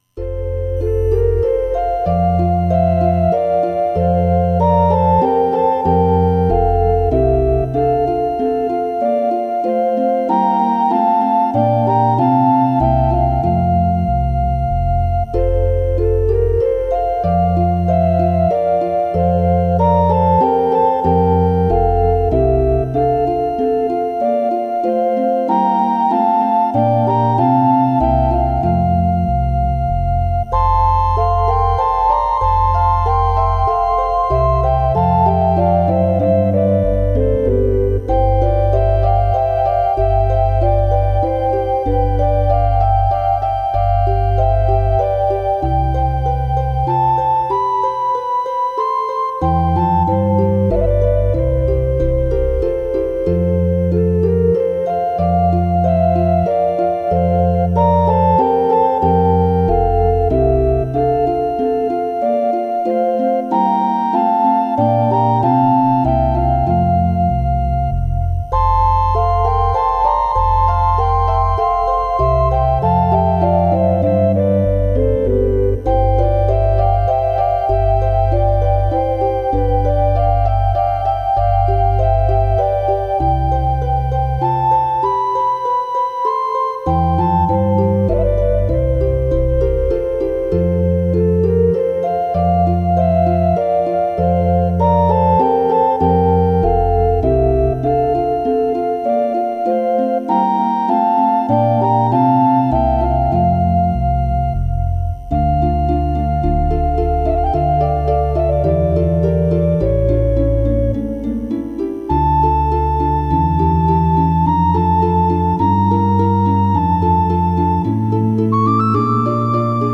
Preludes to worship at the First Church of Christ, Scientist, Brunswick, Maine
on the Allen Organ with orchestral synthesizer at First Church of Christ, Scientist, Brunswick Maine.